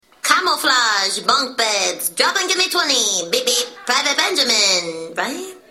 MP3 Custom Car Horns and Ringtones (Showing 20 Results)